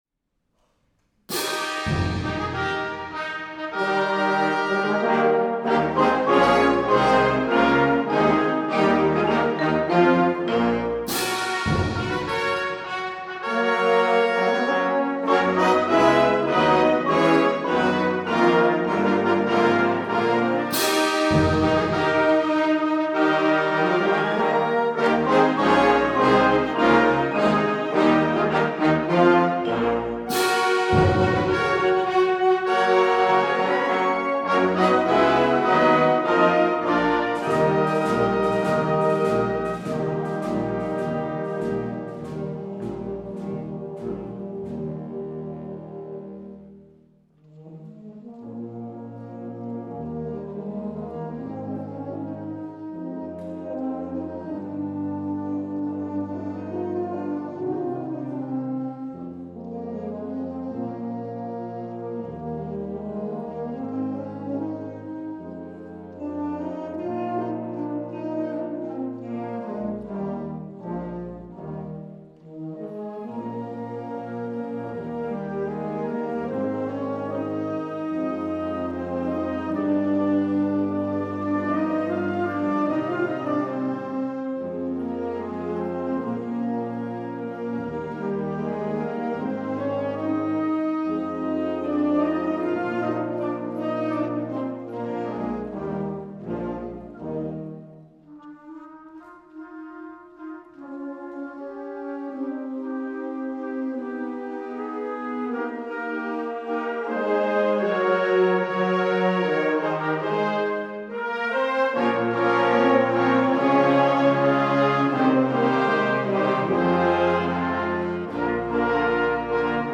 LANDESKONZERTMUSIK - JUGENDBLASORCHESTER
Grafenegg
>live